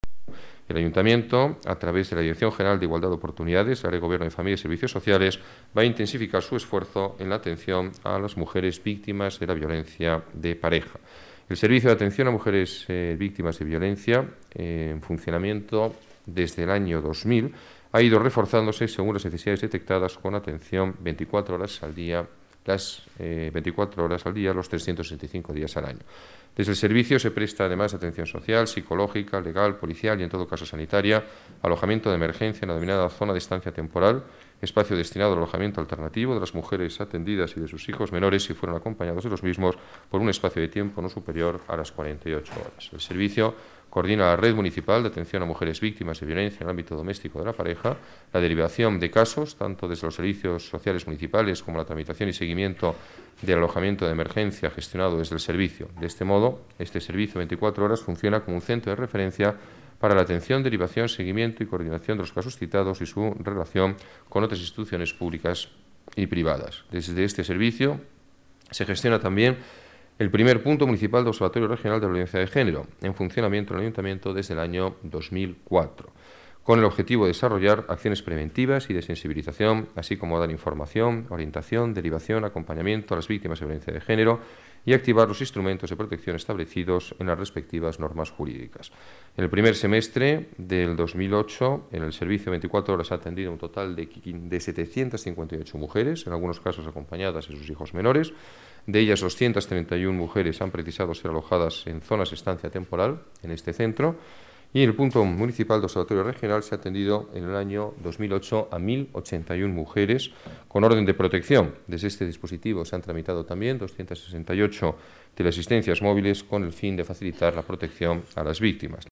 Nueva ventana:Declaraciones alcalde de Madrid, Alberto Ruiz-Gallardón: atención a mujeres maltratadas